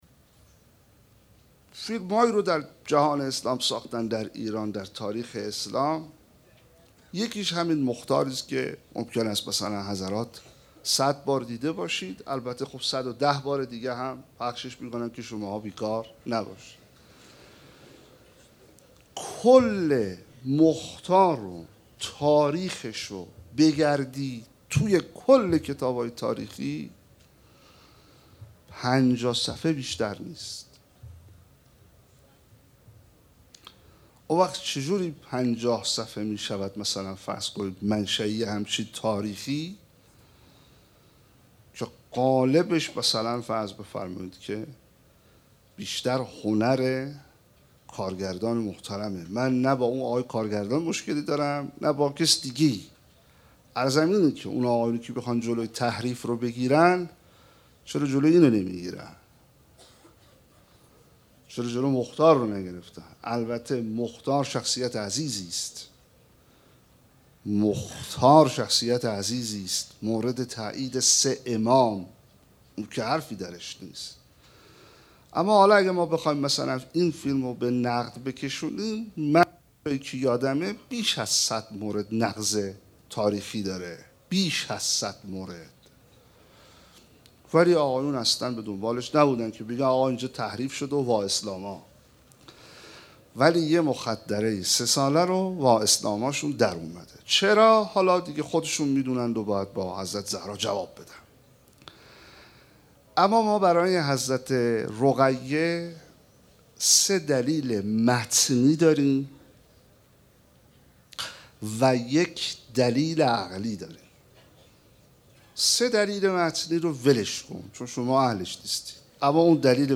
شب سوم محرم 1436
سخنرانی